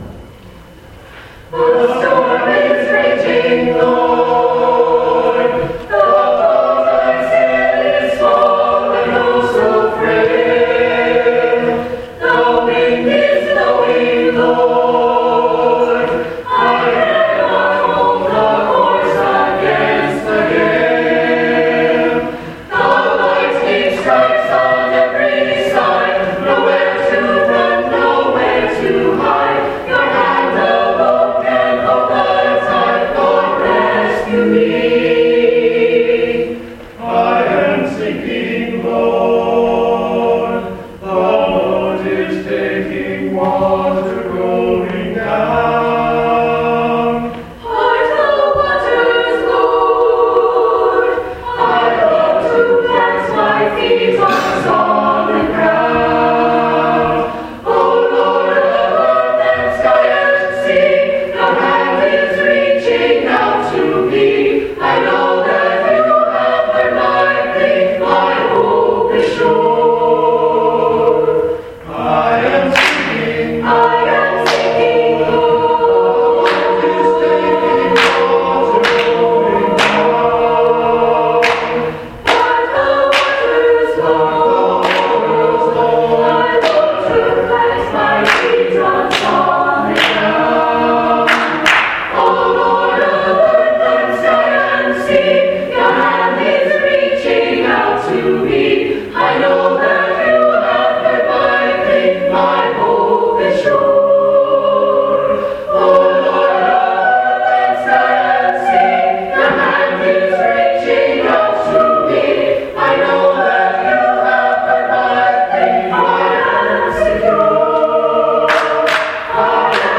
MCC Senior Choir Offertory February 8, 2015